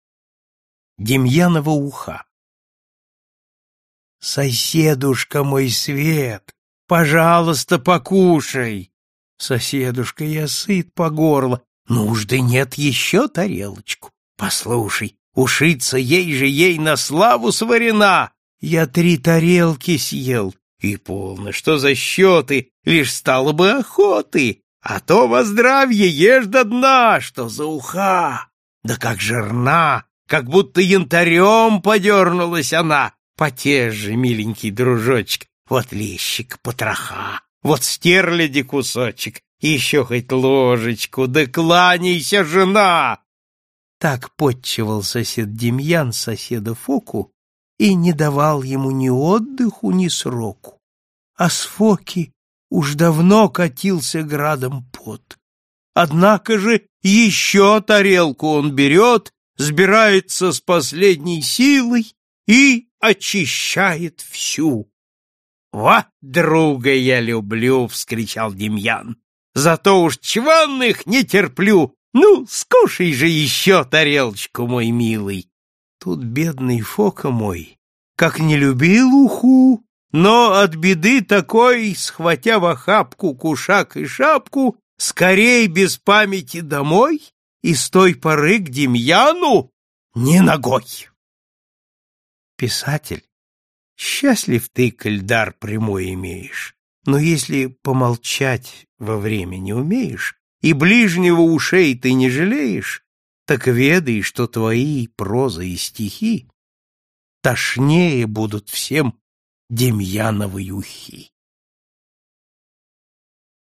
Аудиокнига Русские басни | Библиотека аудиокниг